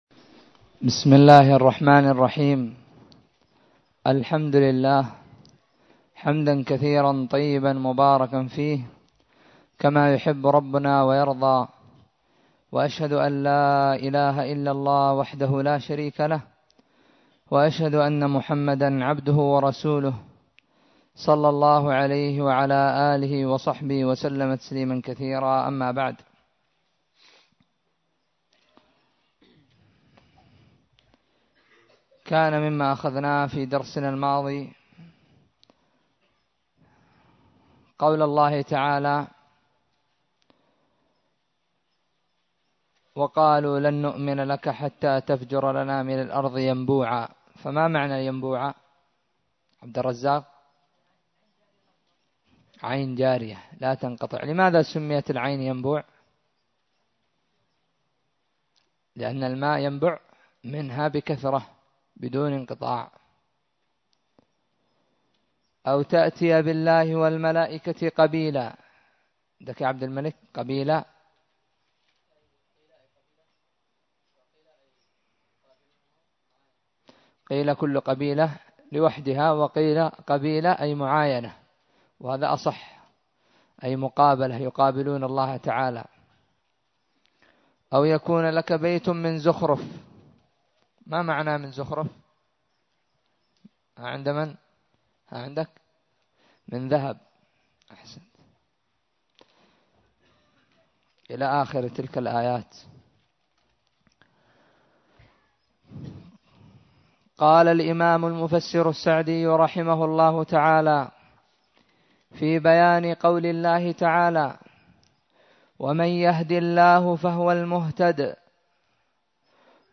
الدرس الثامن والعشرون من تفسير سورة الإسراء
ألقيت بدار الحديث السلفية للعلوم الشرعية بالضالع